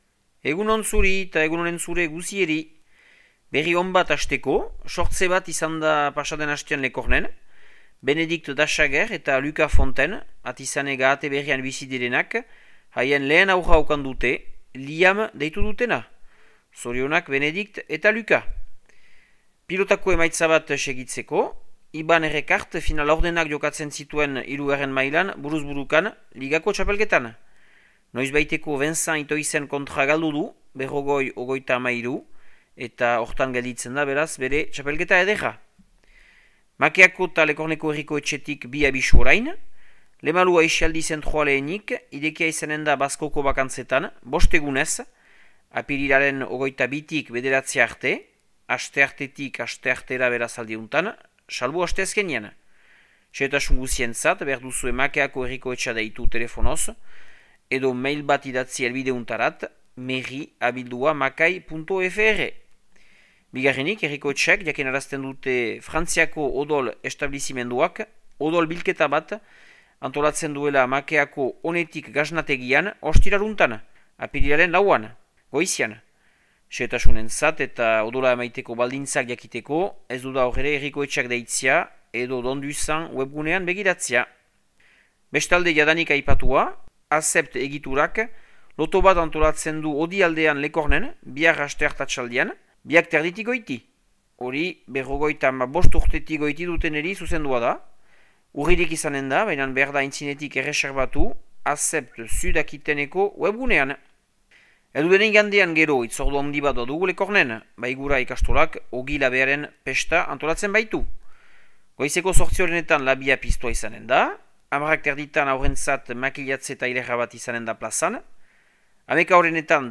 Martxoaren 31ko Makea eta Lekorneko berriak